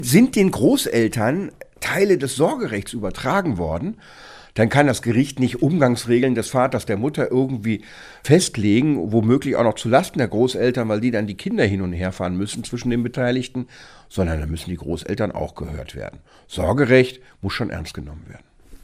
O-Ton: Großvater nicht angehört – Umgangsregelung muss neu verhandelt werden – Vorabs Medienproduktion